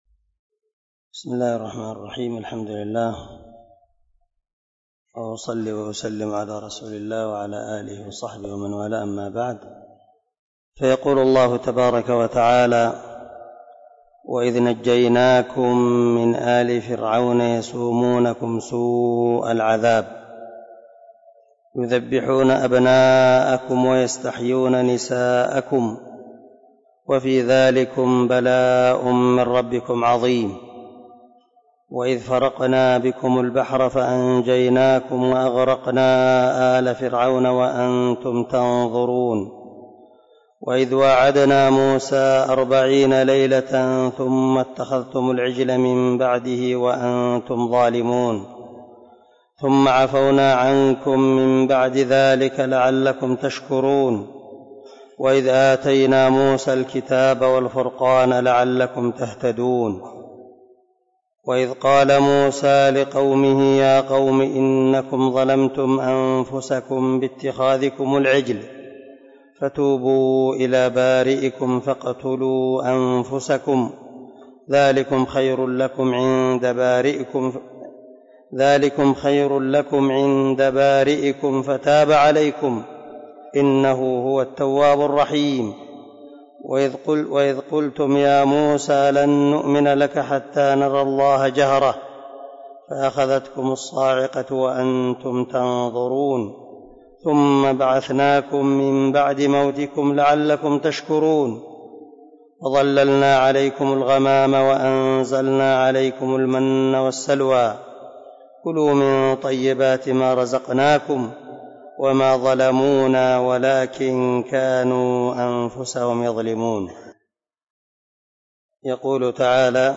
029الدرس 19 تفسير آية ( 49 - 57 ) من سورة البقرة من تفسير القران الكريم مع قراءة لتفسير السعدي